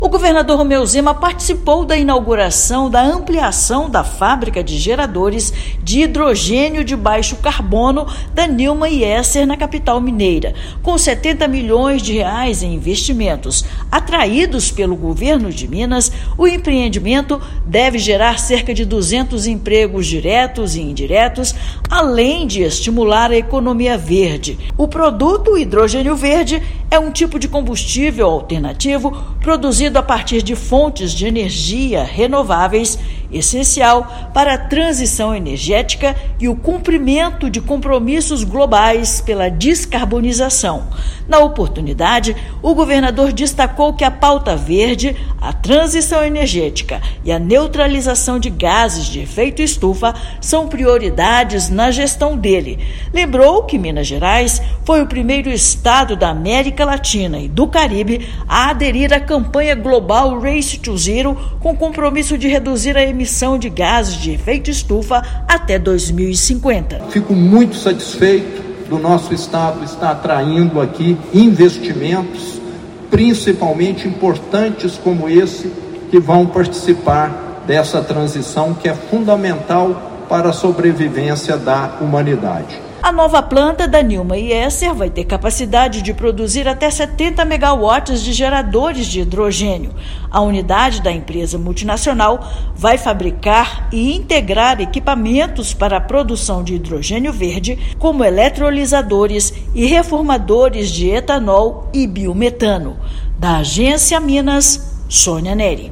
[RÁDIO] Empresa atraída pelo Governo de Minas inaugura expansão de fábrica de gerador de hidrogênio de baixo carbono